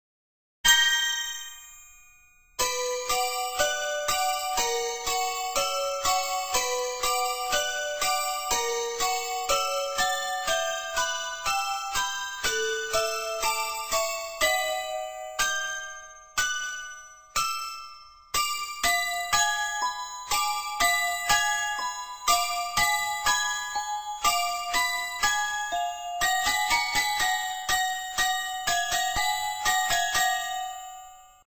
phone ring tone from the movie